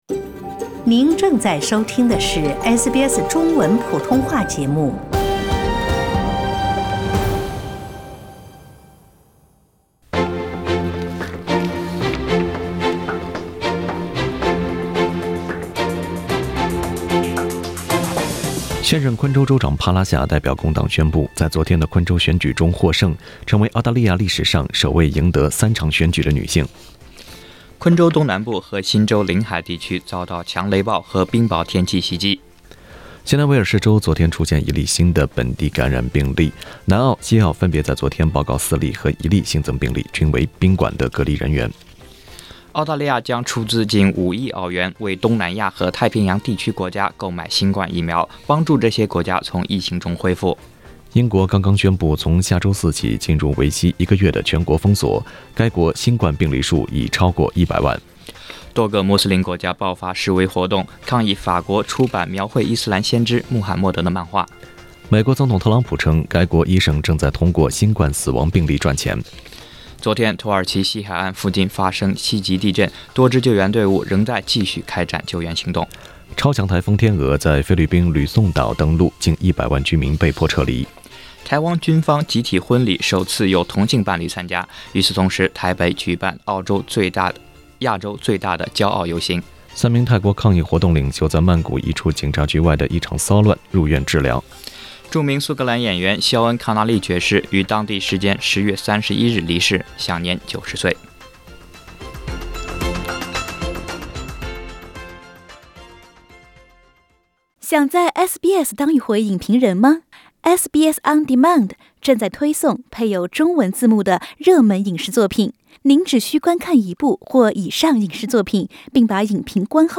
SBS早新闻（11月1日）